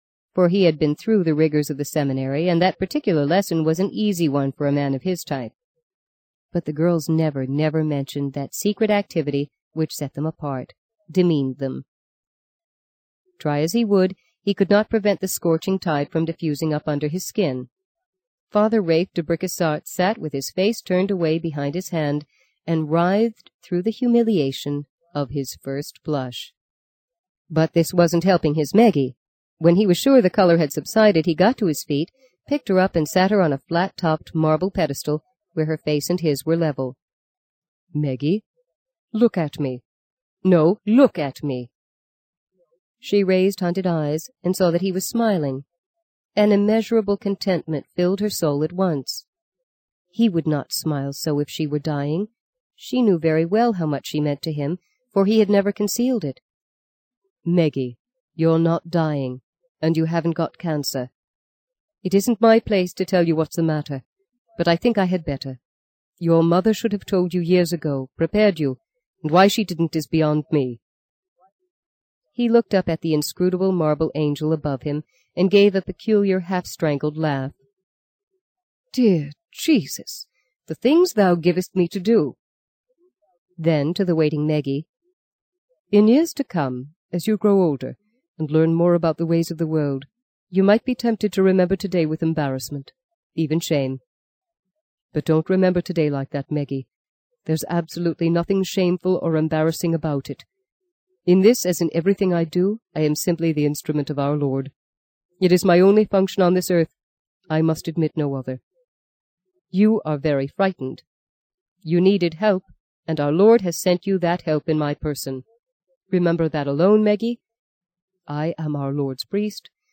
在线英语听力室【荆棘鸟】第六章 23的听力文件下载,荆棘鸟—双语有声读物—听力教程—英语听力—在线英语听力室